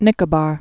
(nĭkə-bär)